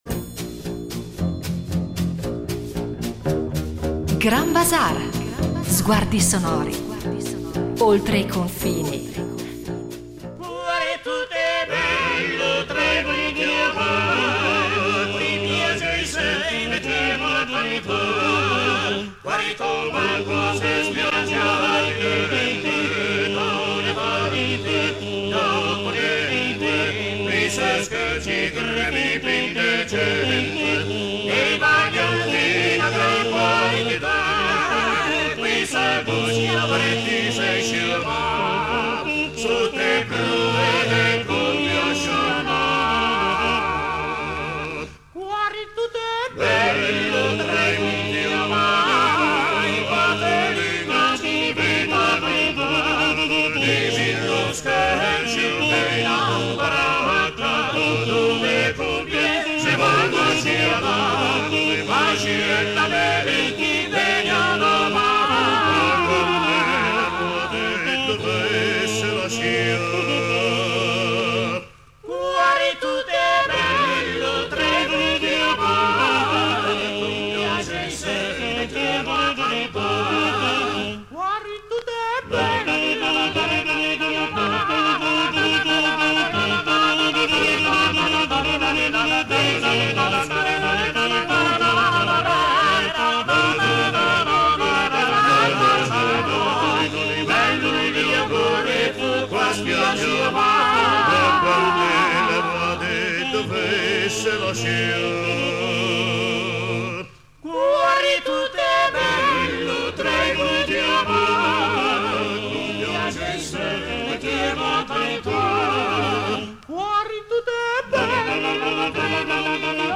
Il canto polifonico tipico della città di Genova